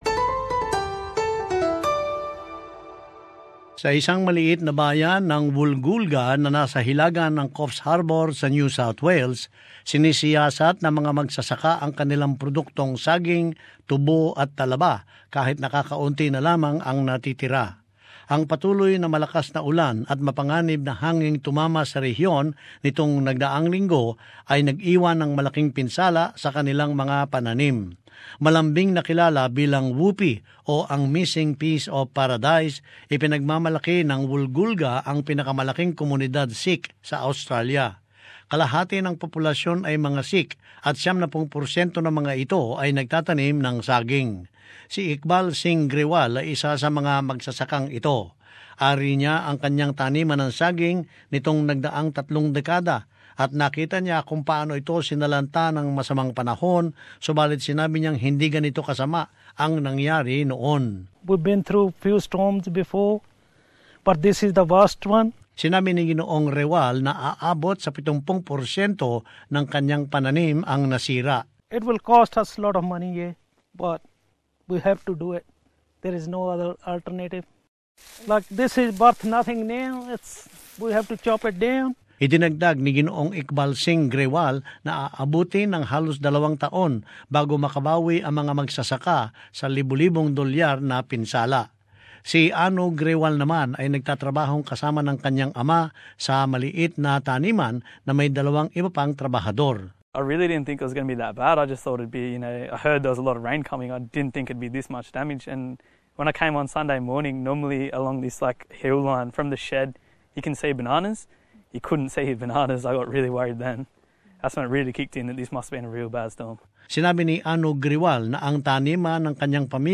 But in this report, some of those hit hardest are insisting they will recover.